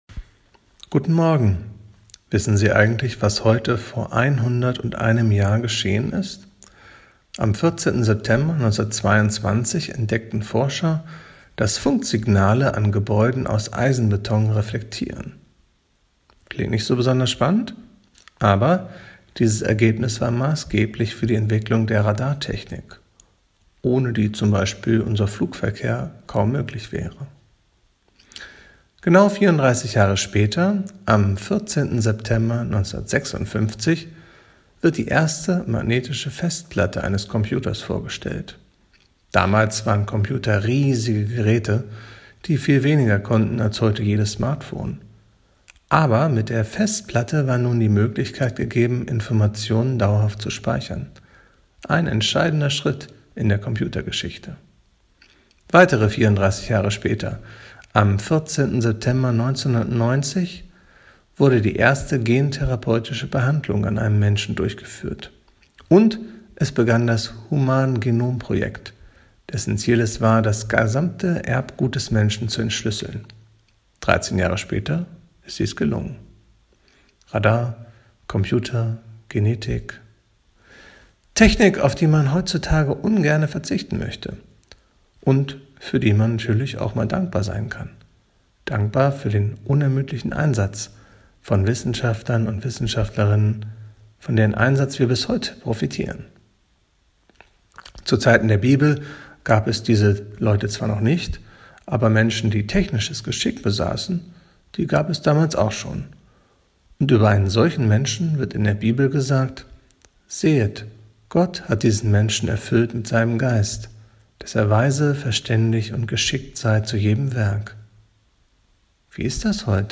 Radioandacht vom 14. September